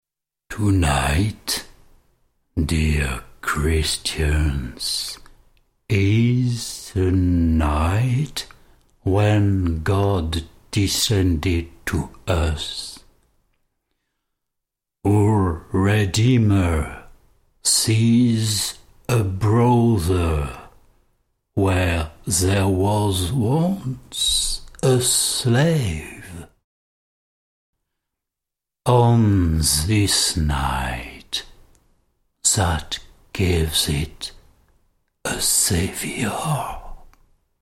Narration conte